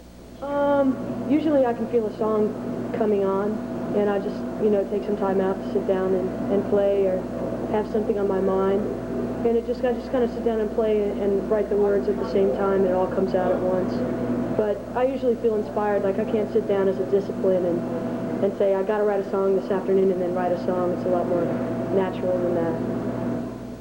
07. interview (0:23)